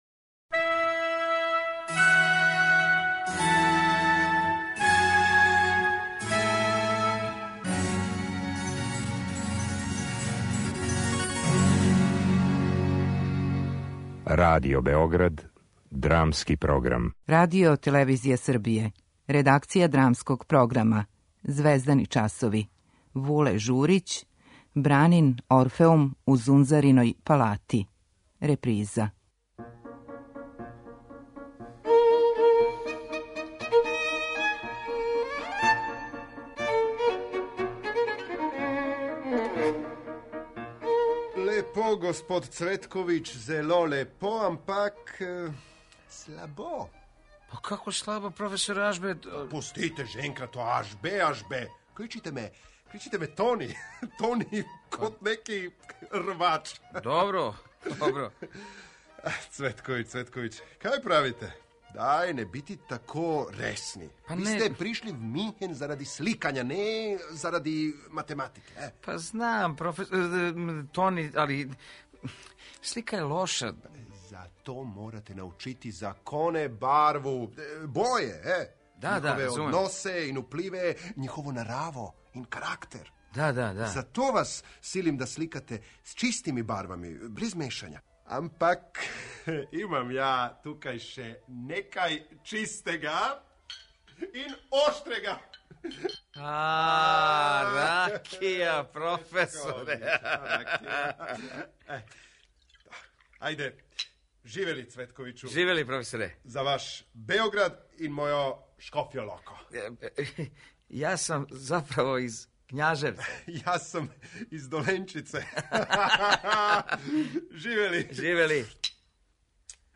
Драмски програм: Звездани часови
Оригинална радио драма писца Вулета Журића о Брани Цветковићу, оснивачу првог хумористично-сатиричног позоришта у Срба - Орфеум, комичару, глумцу, сликару, управнику позоришта у Скопљу и Новом Саду, као и војног позоришта на Крфу.